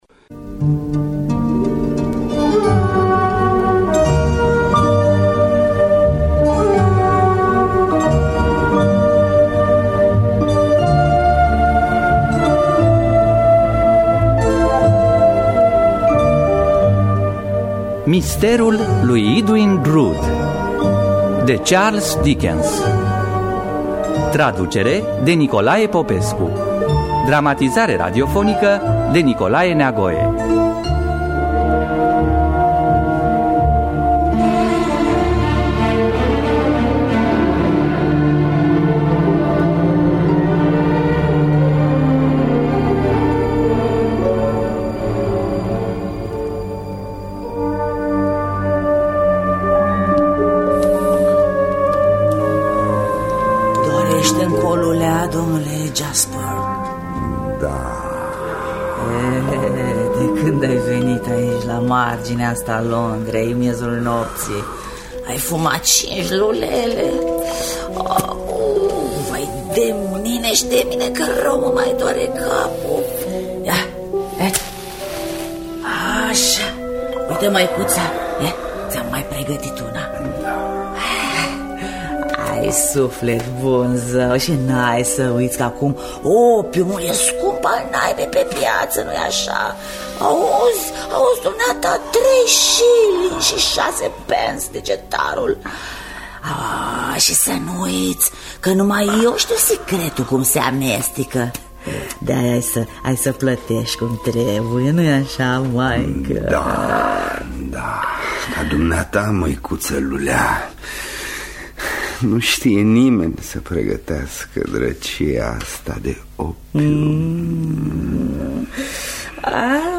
Dramatizarea radiofonică de Nicolae Neagoe.